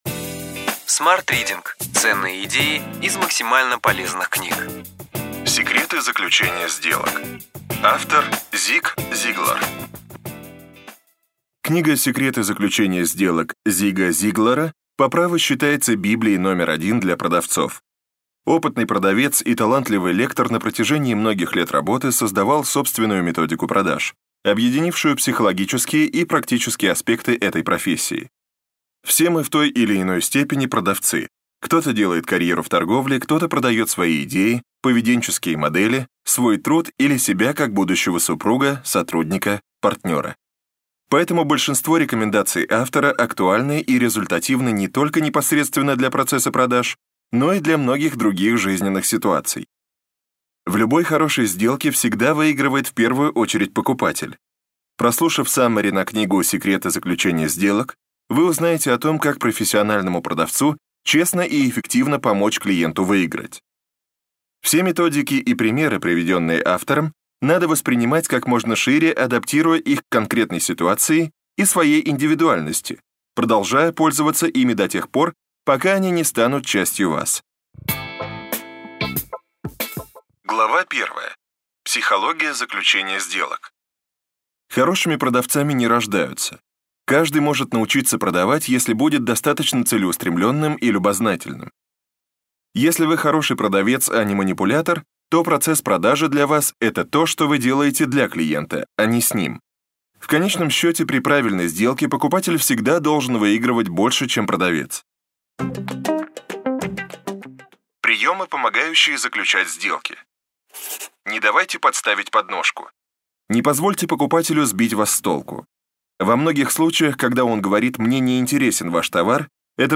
Аудиокнига Ключевые идеи книги: Секреты заключения сделок. Зиг Зиглар | Библиотека аудиокниг